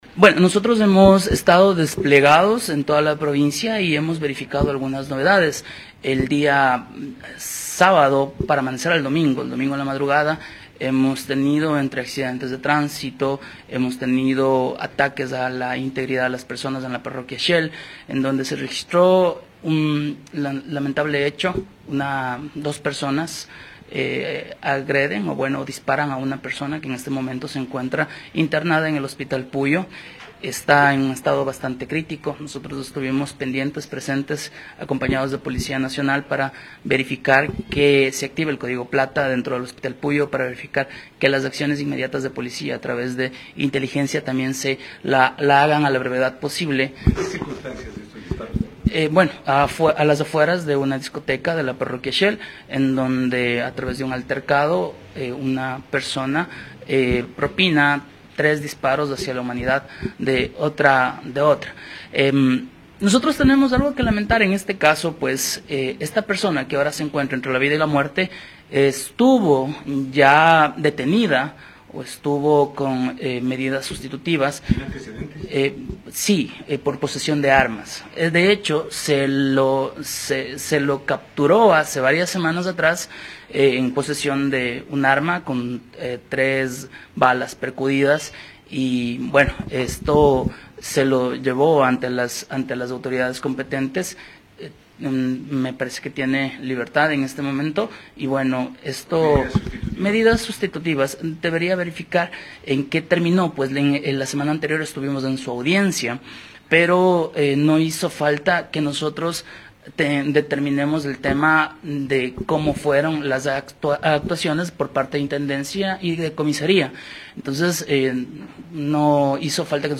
Ing. Rolando Ramos, gobernador de Pastaza.